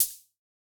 Index of /musicradar/retro-drum-machine-samples/Drums Hits/Tape Path B
RDM_TapeB_MT40-OpHat01.wav